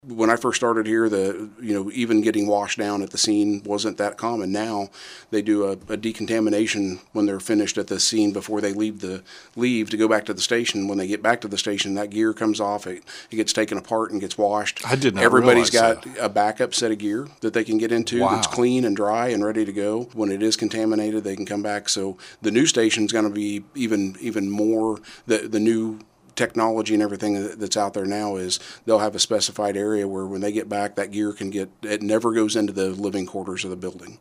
The Chief joined in on the KSAL Morning News Extra with a look at life in a fire station. He says over the years a focus on cancer prevention and keeping crew members safe from toxic vapors is now on the forefront.
Fire-Chief-on-toxics.mp3